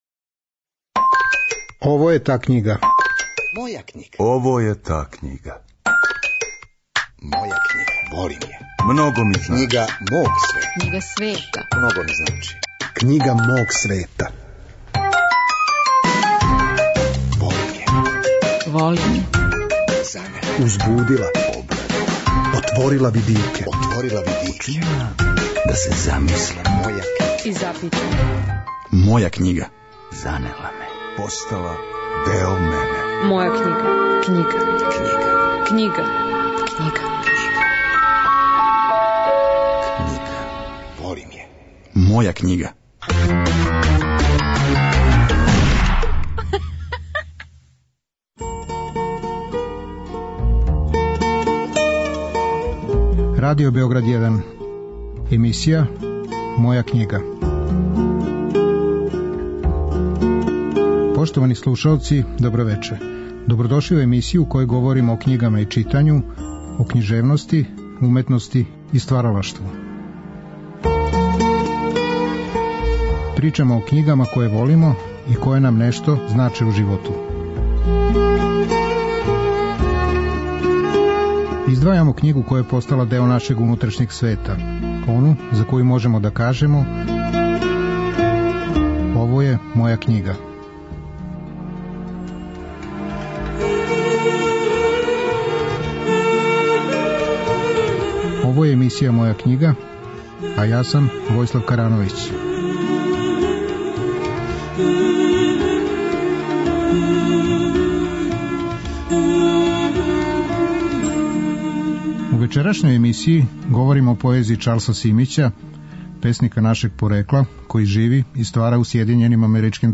Емисија о књигама и читању, о књижевности, уметности и стваралаштву.